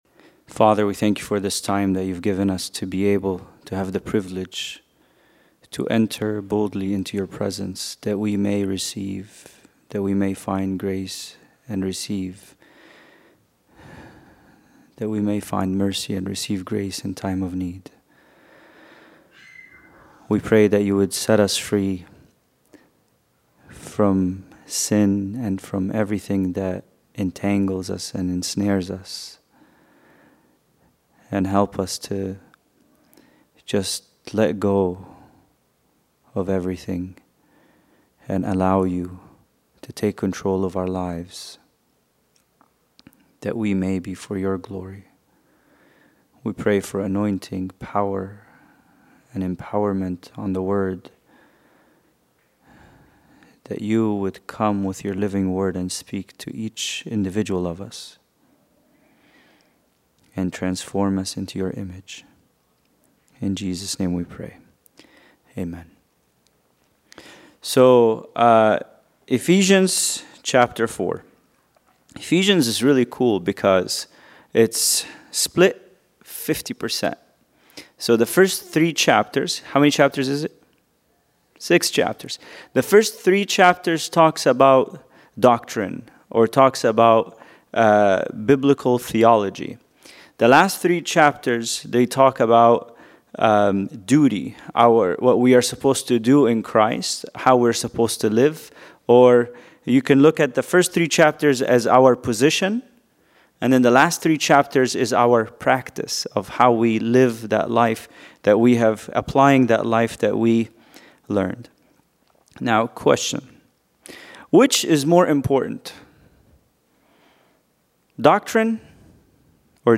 Bible Study: Ephesians 4:1-3